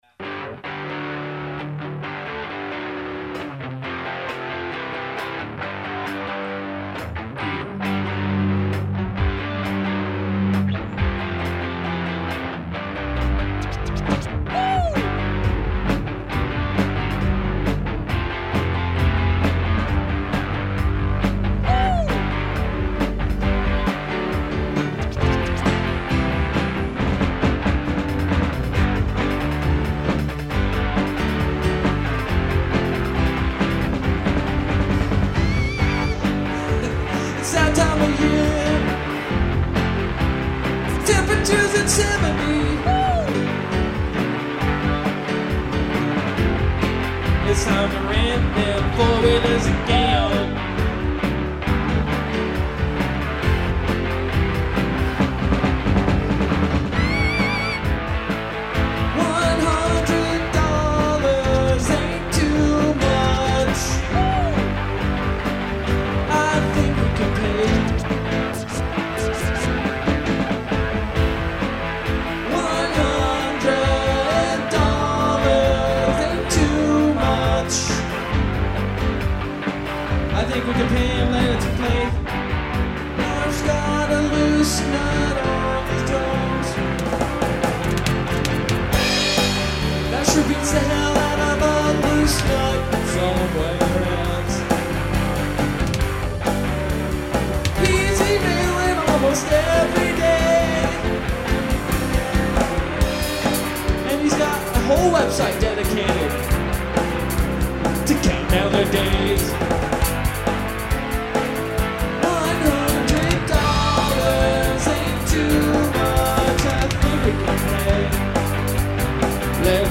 Keys